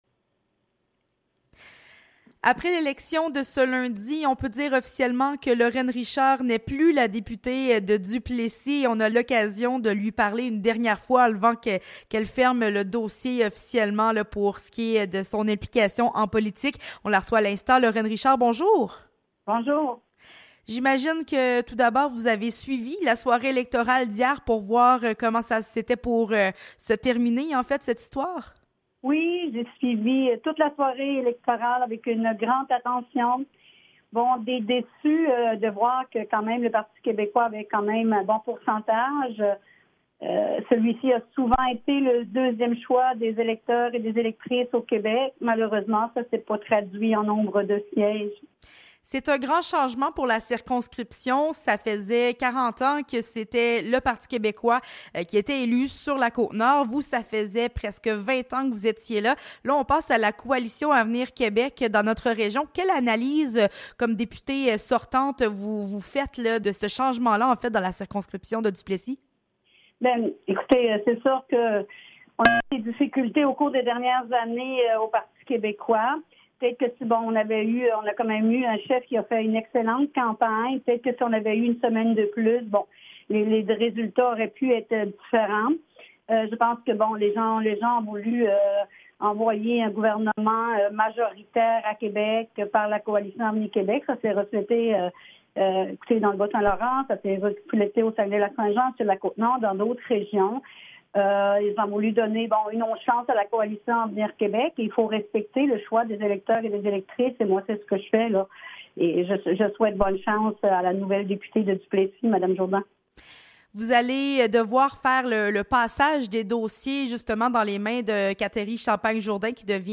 Lors d’une dernière entrevue accordée à la radio CFMF, elle a indiqué qu’elle partira avec de bons souvenirs de sa carrière, qui aura duré près de 20 ans :